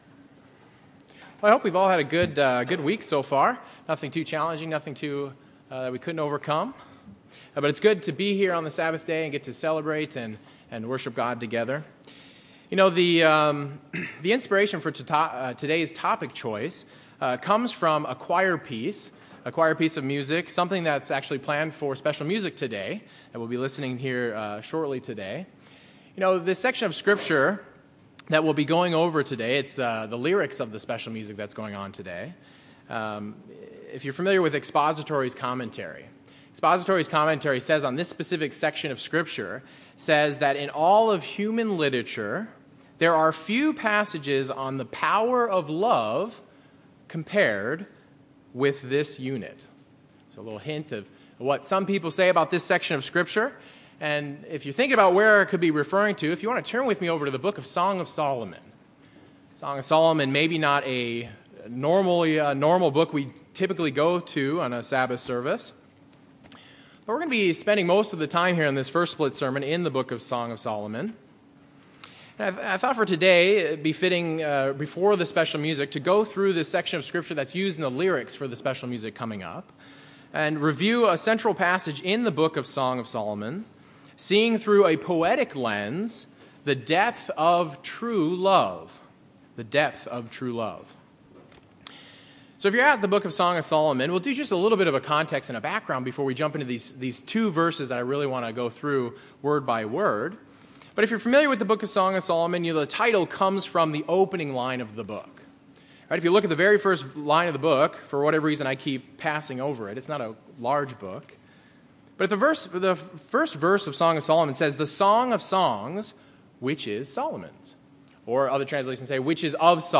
These poems culminate in the 8th chapter of the book, where we find a wonderful statement on the depth and power of love itself. This sermon reviews Song of Solomon 8:6-7 and the spiritual meaning for us today.